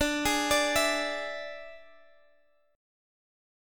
Listen to Dsus2b5 strummed